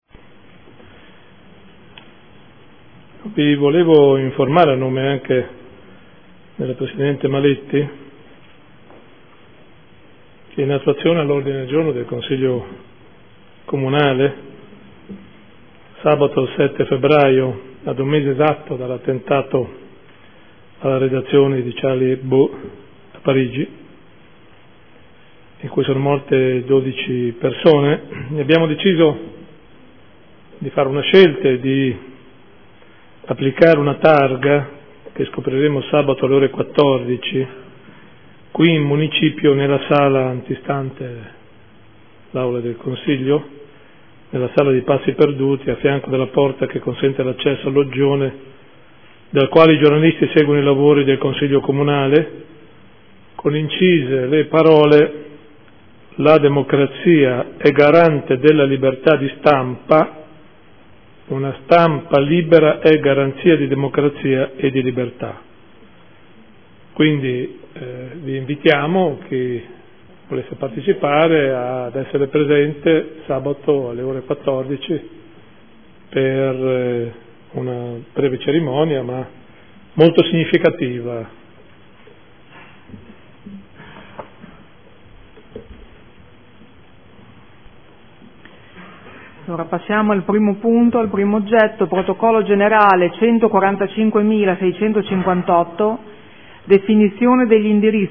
Seduta del 05/02/2015 Comunicazione. Una targa per la libertà di stampa.